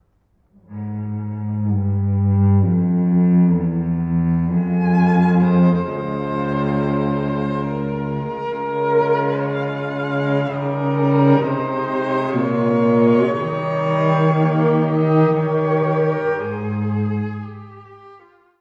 ↑古い録音のため聴きづらいかもしれません！（以下同様）
この曲で唯一の長調です。
ただし、長調といっても明るくはなく、憂いを含んだ翳りのある音楽です。
まるで、姉に向けたレクイエムのようです。